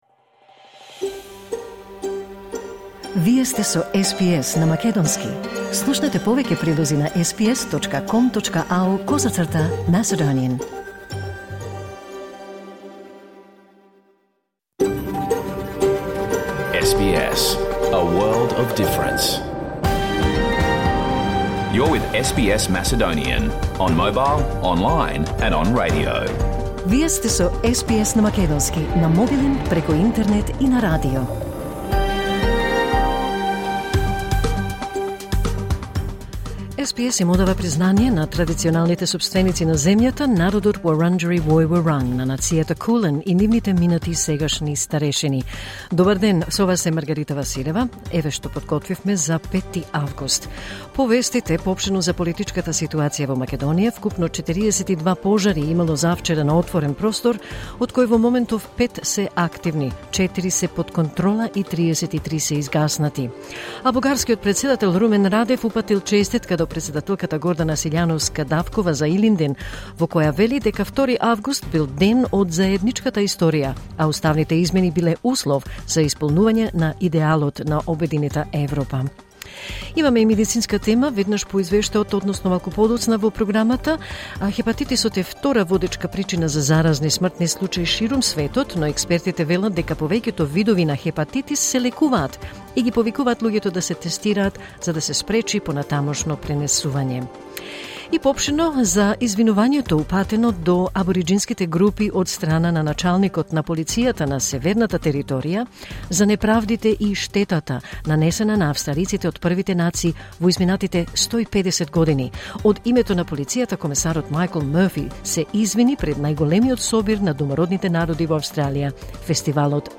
Програмата на СБС на македонски емитувана во живо на 5 август 2024